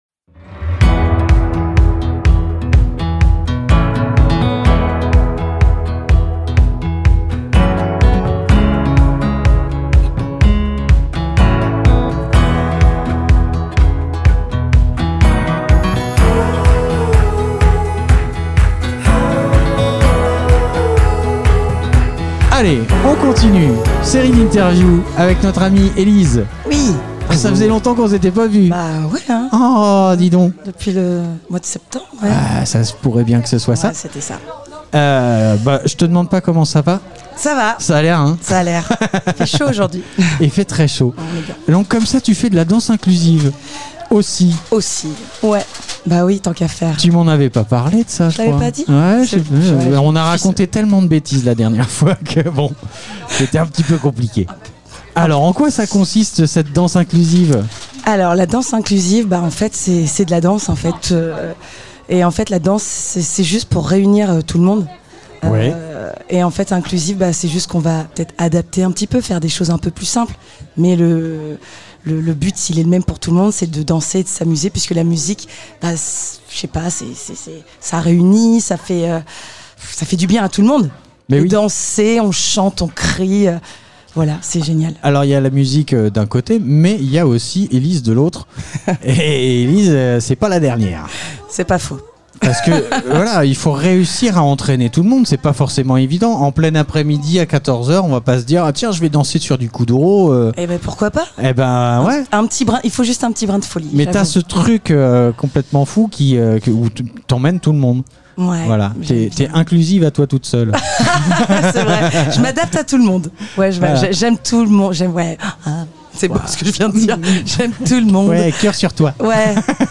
La nuit du handicap événement Interview handicap fécamp ville de fécamp nuit ville Nuit du Handicap